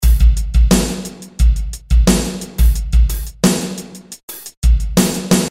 标签： 88 bpm Rap Loops Drum Loops 939.87 KB wav Key : Unknown
声道立体声